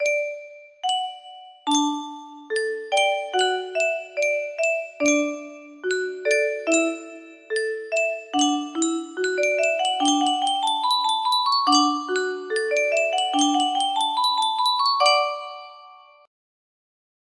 Yunsheng Music Box - Beethoven Symphony No. 5 2889 music box melody
Full range 60